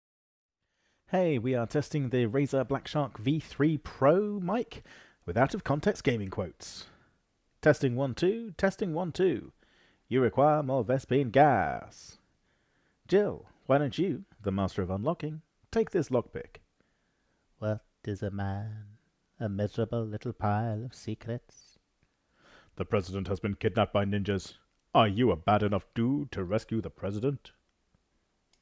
Micro 12 mm mới cũng khá tốt, đặc biệt khi tôi thử nghiệm với các cài đặt EQ.
Default EQ: